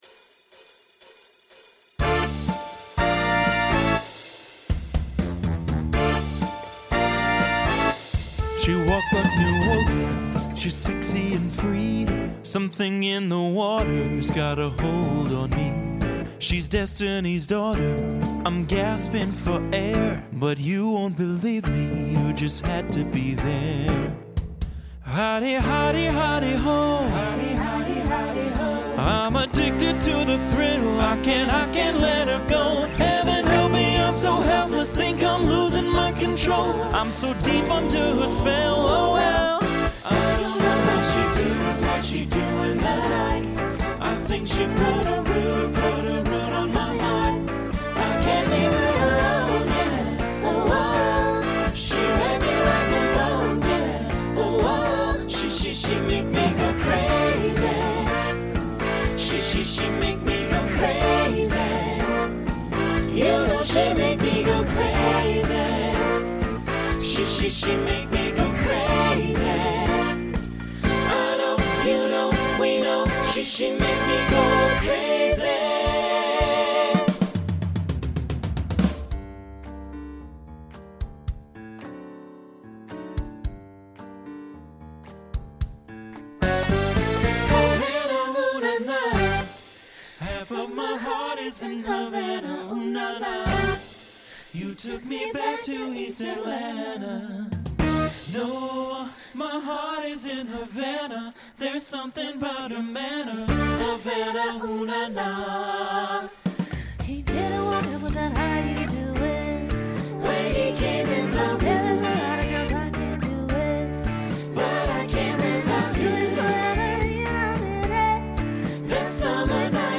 Genre Latin
Voicing Mixed